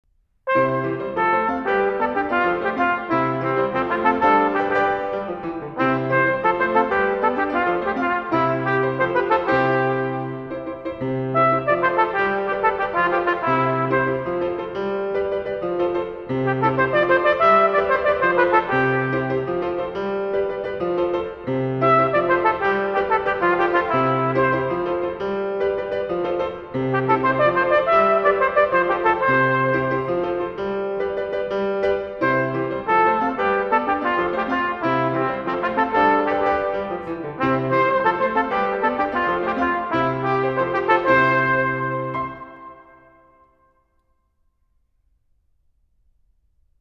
Gattung: Trompete und Klavier inkl.